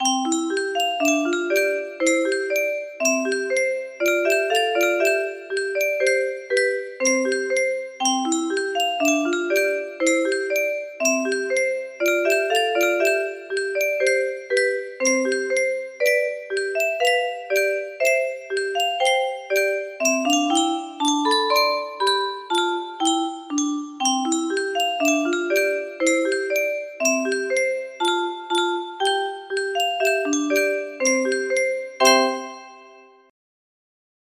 Deck the halls music box melody